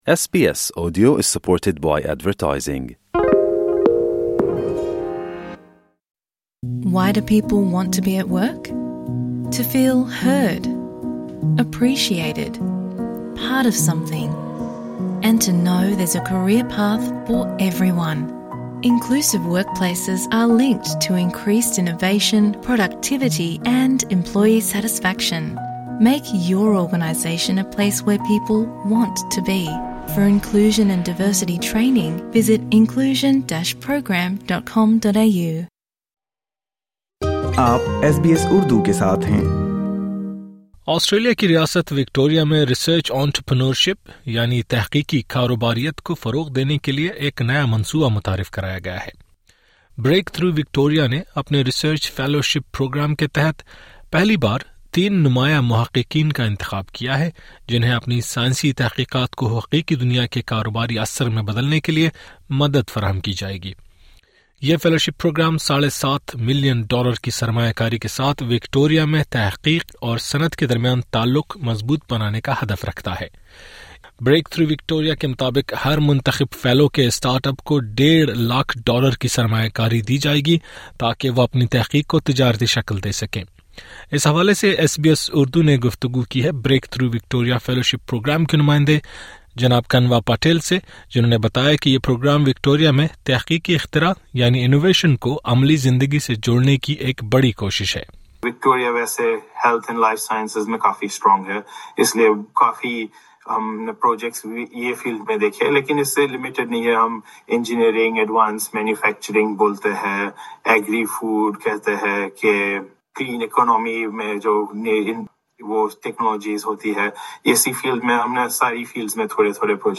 گفتگو۔ LISTEN TO تحقیق سے تجارت تک کا سفر — وکٹوریہ کی نئی فیلوشپ نئی امیدوں کی بنیاد SBS Urdu 06:25 Urdu یہ فیلوشپ پروگرام ساڑھے سات ملین ڈالر کی سرمایہ کاری کے ساتھ وکٹوریا میں تحقیق اور صنعت کے درمیان تعلق مضبوط بنانے کا ہدف رکھتا ہے۔